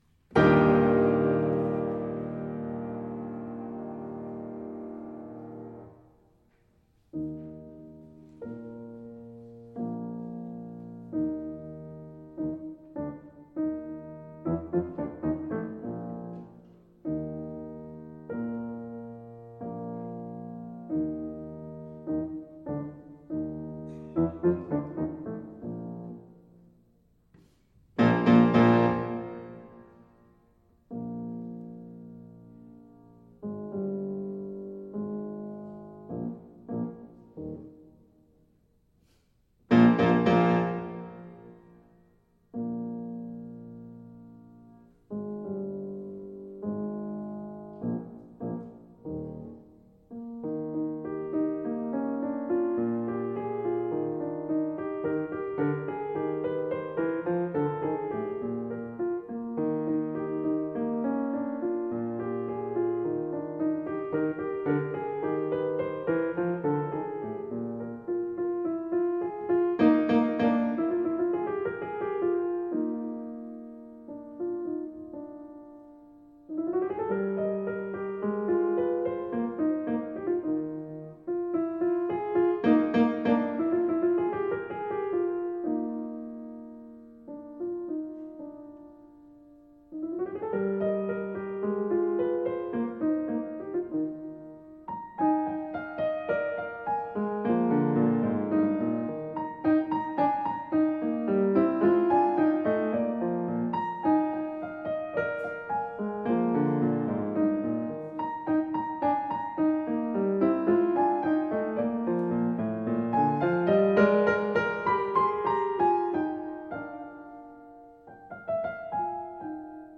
Piano version
piano
Style: Classical
Audio: Boston - Isabella Stewart Gardner Museum